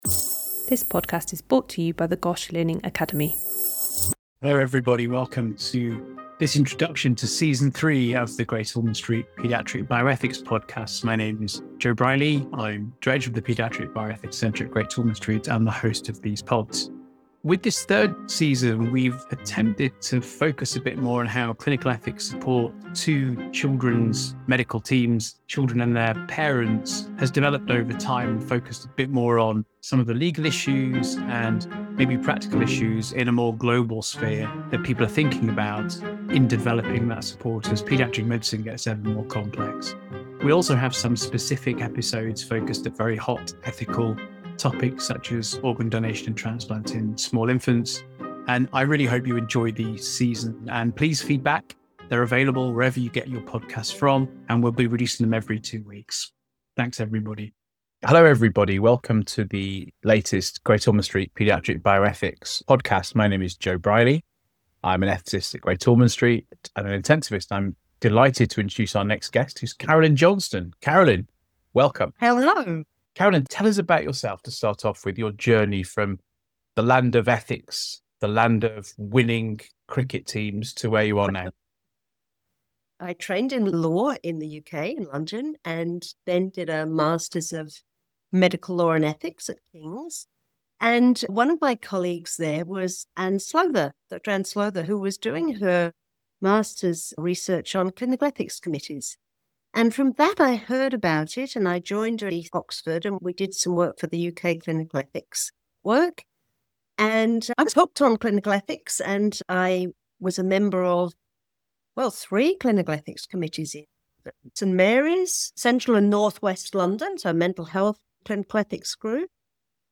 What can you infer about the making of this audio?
These podcasts explore the ethical, legal, and social questions shaping clinical practice.